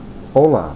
Click on the Spanish word to hear it pronounced.